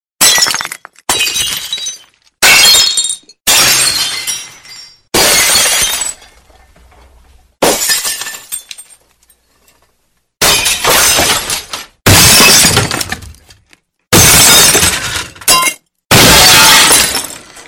breaking-glasses_24707.mp3